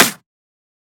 edm-clap-58.wav